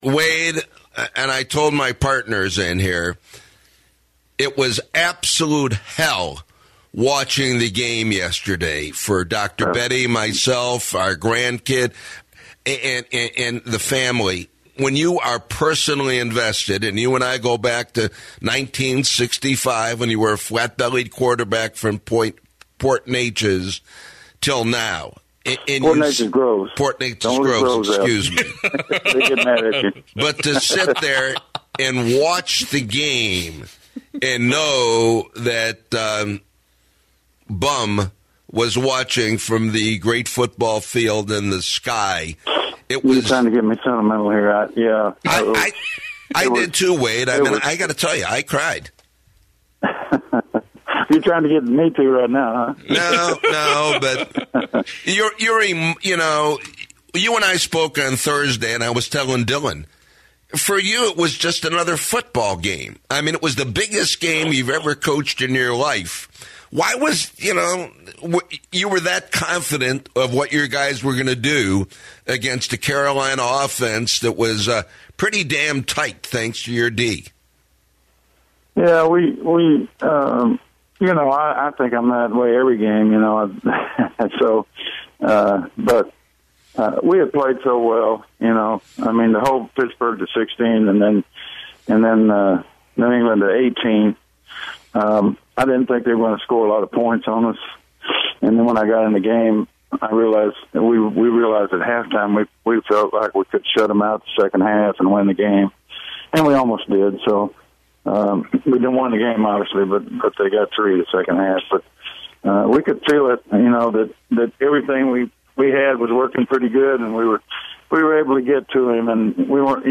Denver Broncos defensive coordinator Wade Phillips, joins "Reality Check" to answer everything Super Bowl 50.